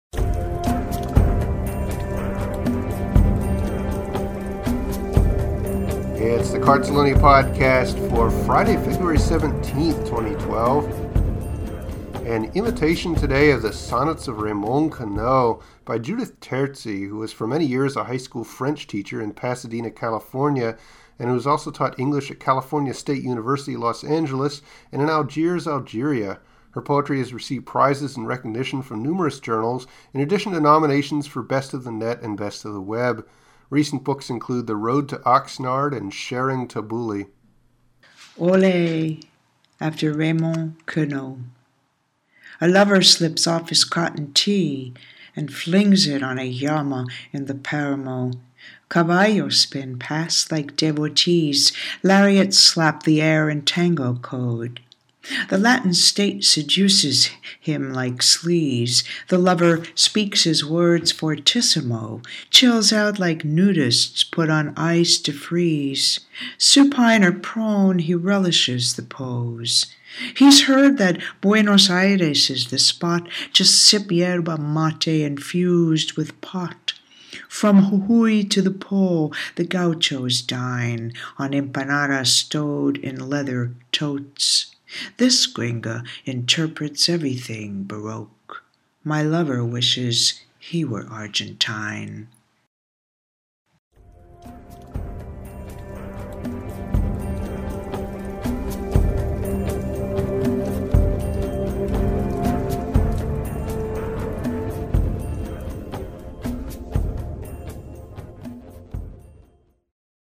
What a pleasure to hear you read this! Seductive and charming!